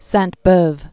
(săɴt-bœv), Charles Augustin 1804-1869.